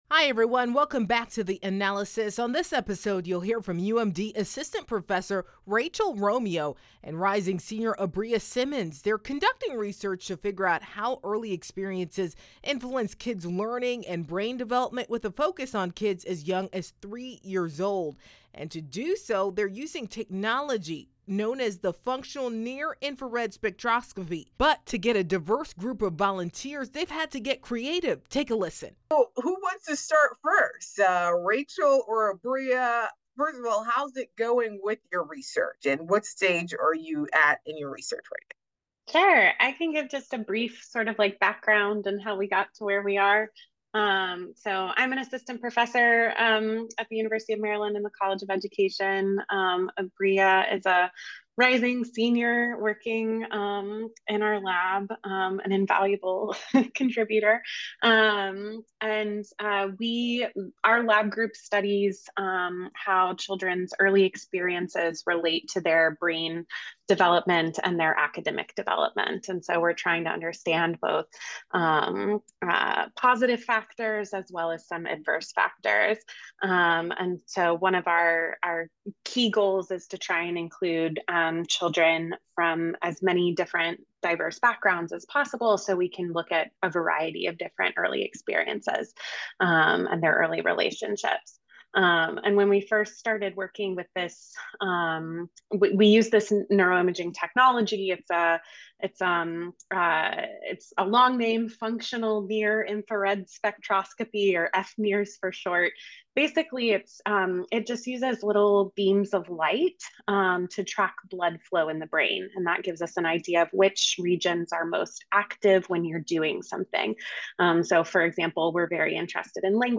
or listen to this episode for the extended conversation